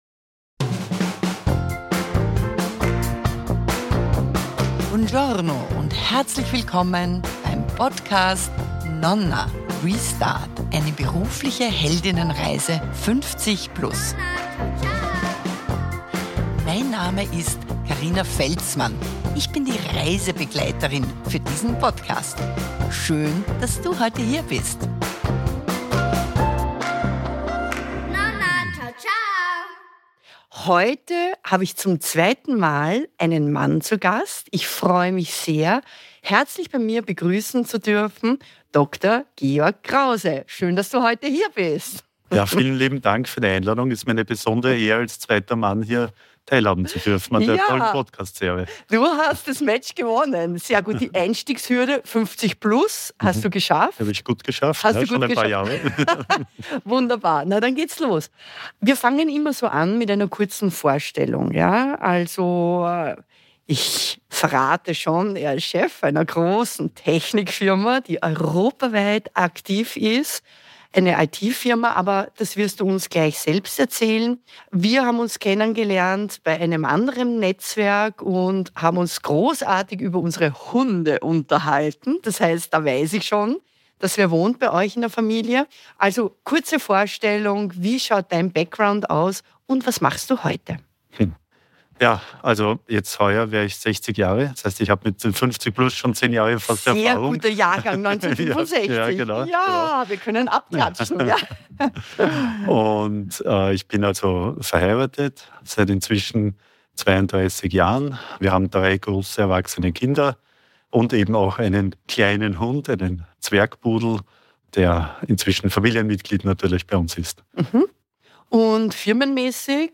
Welche Rolle spielen Werte in seiner Karriere, wie hat er seine berufliche Veränderung mit 50+ angepackt? Ein inspirierendes Gespräch über seine Karriere und IT…